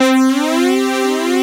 POLICE.wav